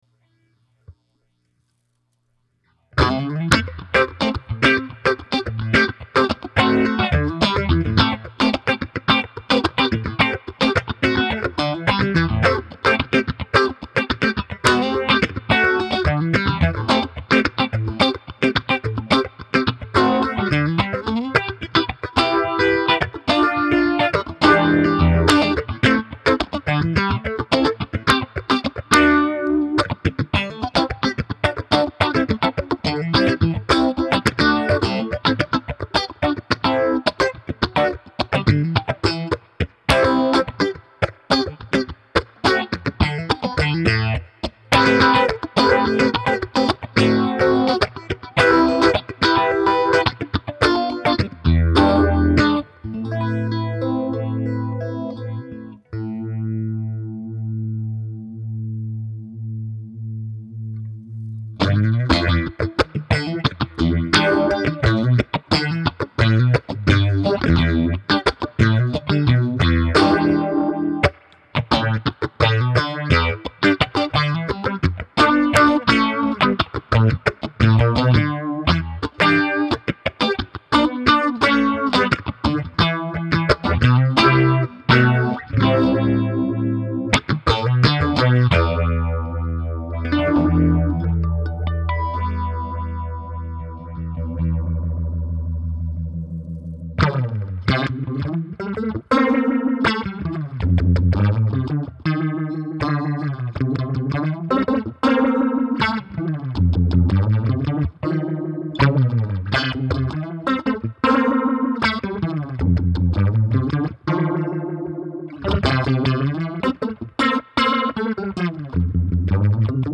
The Sketchy Zebra is a 6-stage vibrato / phase shifter with a huge range of spacey sounds. From a little warble to a full on phased out space travel, the Sketchy Zebra has you covered.
Sketchy Zebra, Strat, into Princeton Reverb
Mayfly-Sketchy-Zebra---phaser-vibrato-----Strat-into-Princeton-Reverb-Style.mp3